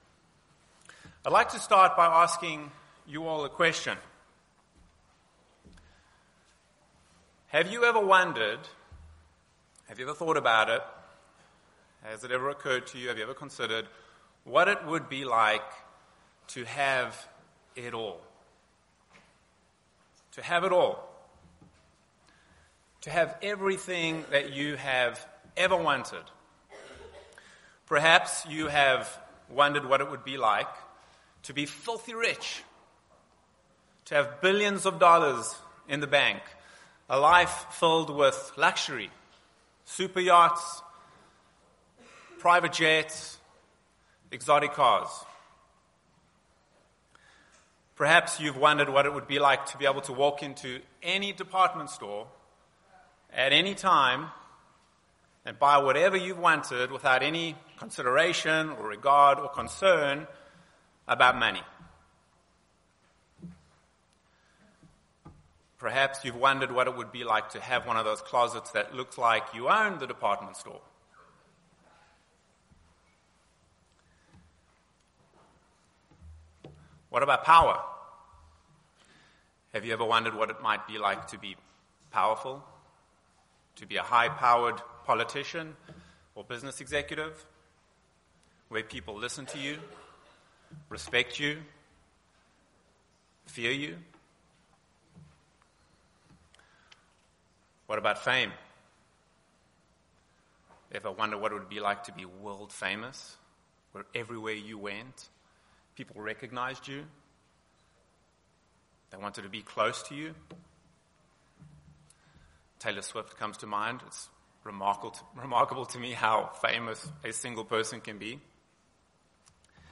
This Sabbath seminar was given during the 2024 Winter Family Weekend in Cincinnati, Ohio.